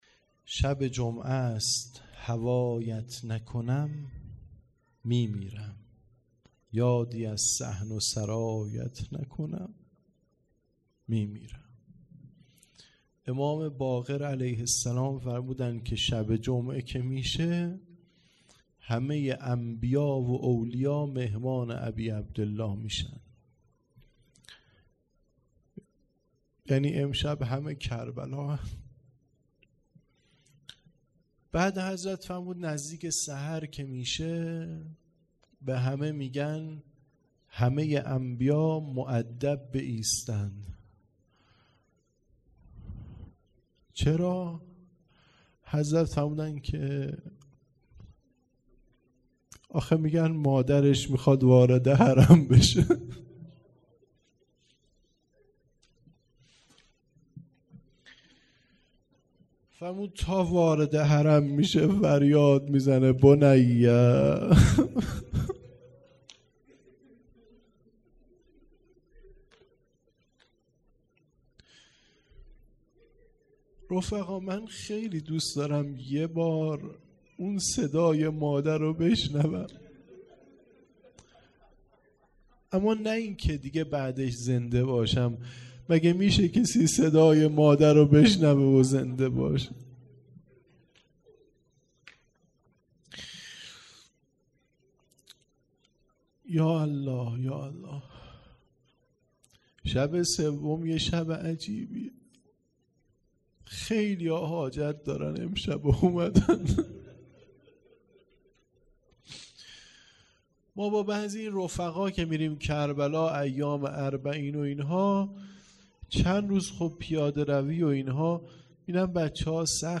مراسم عزاداری محرم ۱۴۰۲